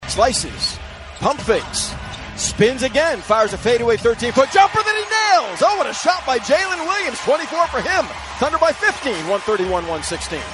Thunder PBP 4-9 .mp3